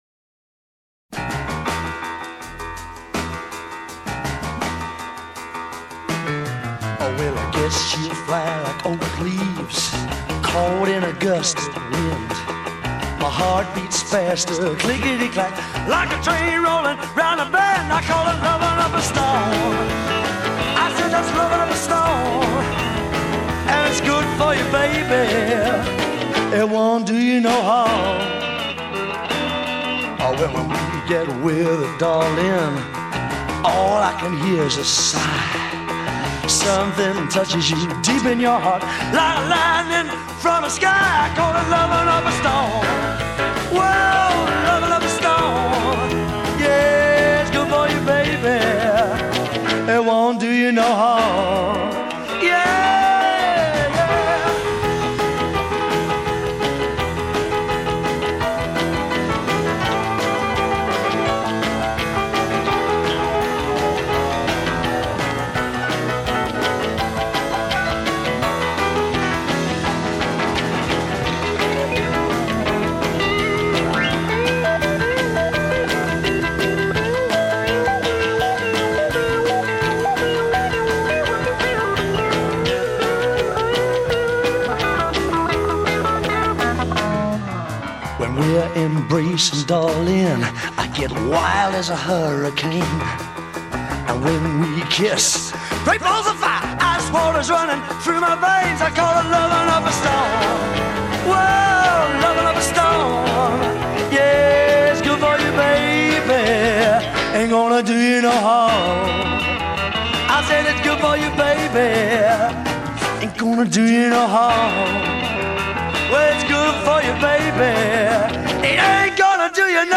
Rock, Blues, Rockabilly